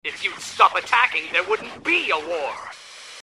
Voice 1 -
Sex: Male